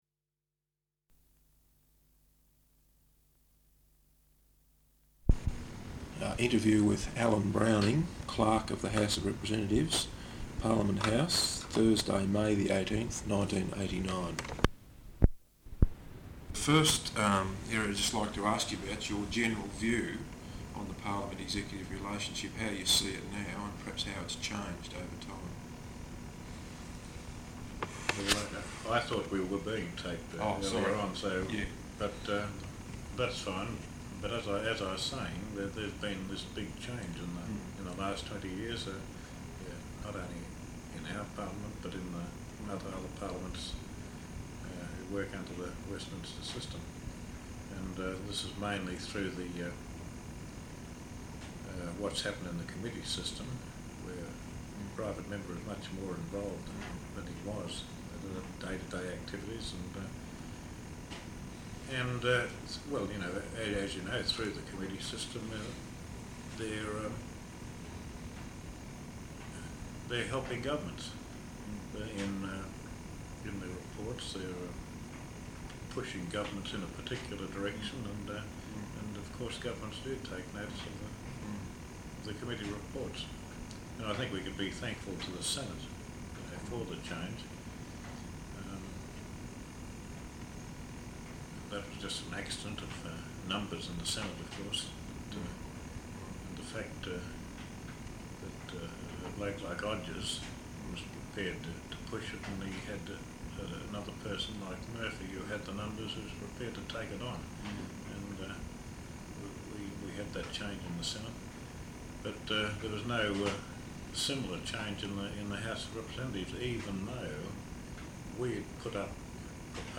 Interview with Alan Browning, Clerk of the House of Representatives, Parliament House, Thursday May 18th 1989.